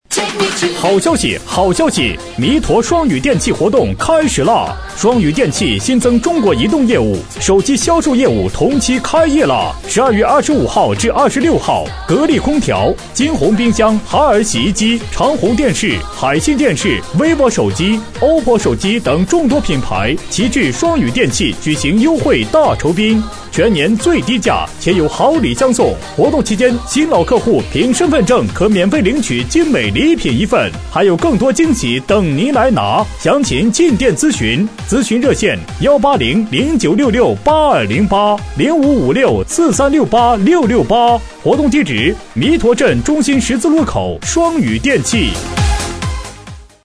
2225男声家具电器促销
2225男声家具电器促销.mp3